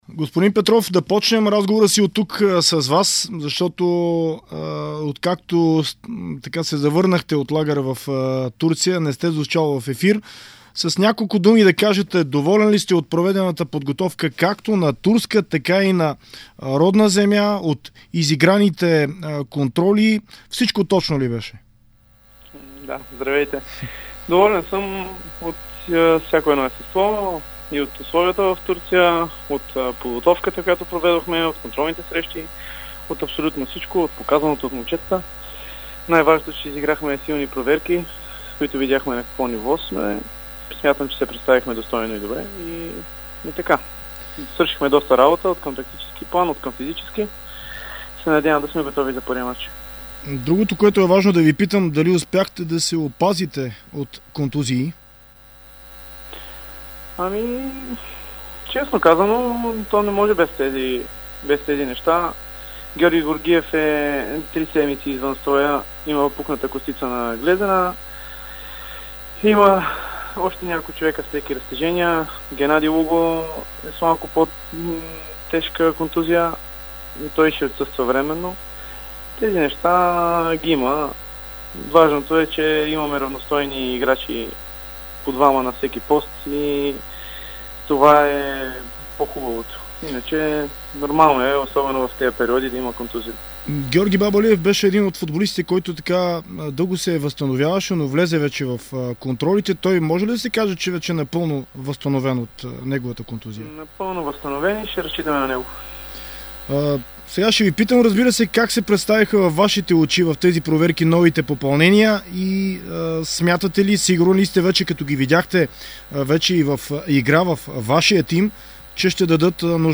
В интервю за дарик радио и dsport